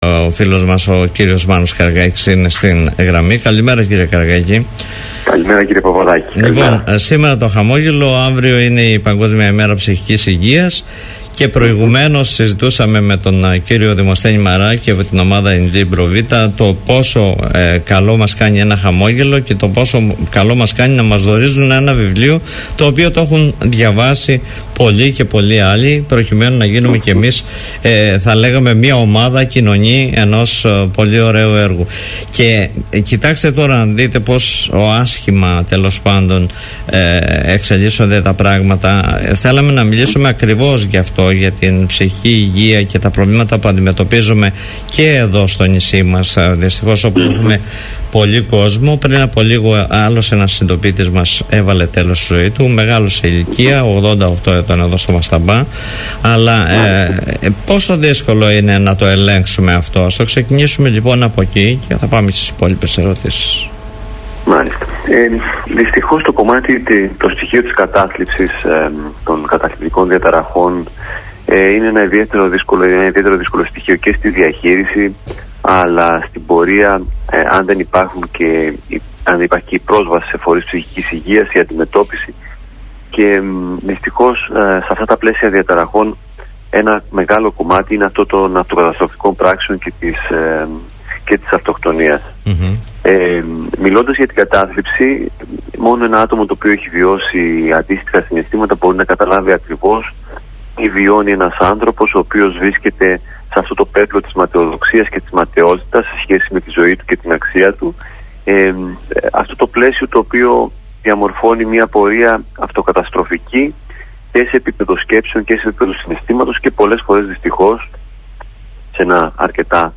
Η 10η Οκτωβρίου έχει θεσπιστεί από τον Παγκόσμιο Οργανισμό Υγείας (ΠΟΥ) ως Παγκόσμια Ημέρα Ψυχικής Υγείας, με αφορμή την αυριανή αυτή μέρα μίλησε στoν Politica 89.8